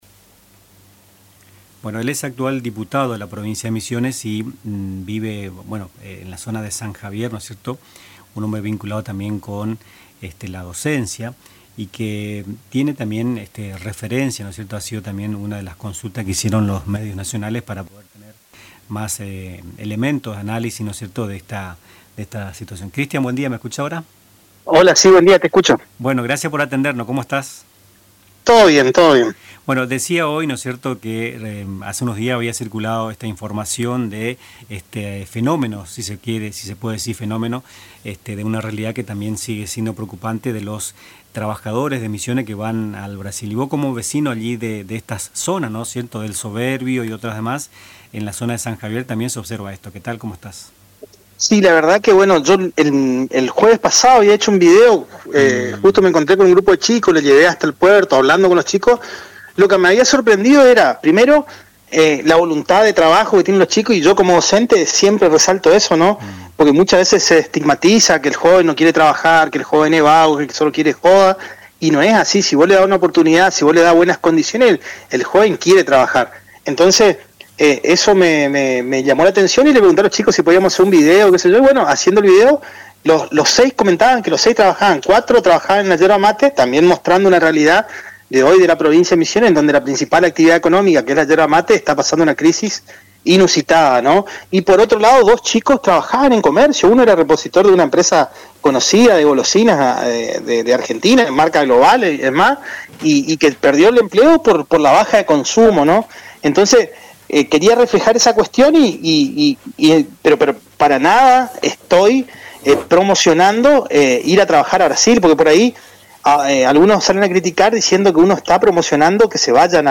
Cristian Castro, diputado provincial del Partido Agrario y Social (PAYS), dialogó con Nuestras Mañanas sobre la creciente salida de trabajadores misioneros hacia Brasil y la difícil situación que atraviesan los sectores productivos locales, especialmente la yerba mate y el tabaco.